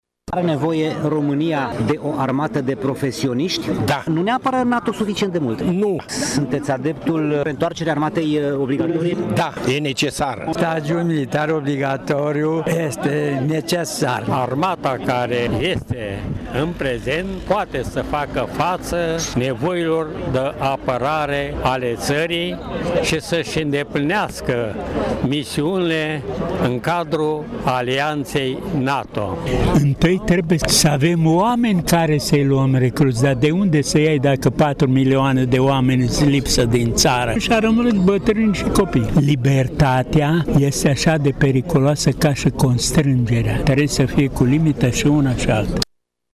Veteranii de război cred în utilitatea armatei, chiar în necesitatea unei armate de recruti:
veterani-razboi.mp3